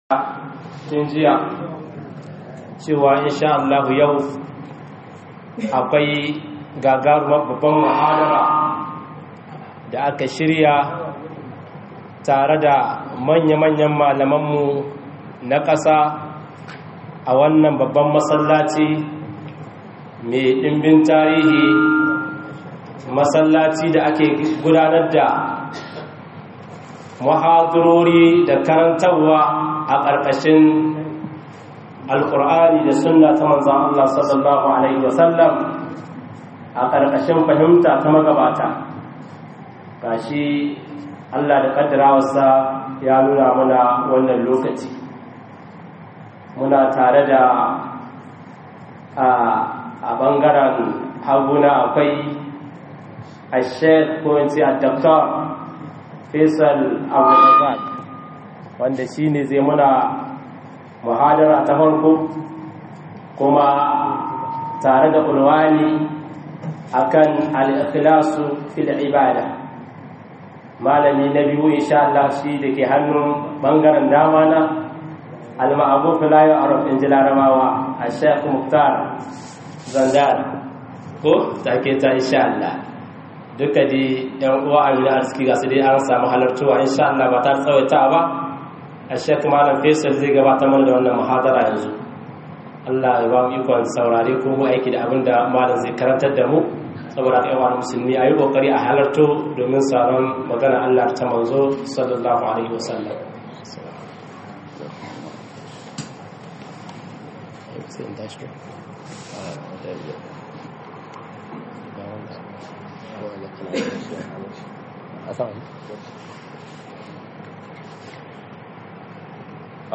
YI DAN ALLAH - MUHADARA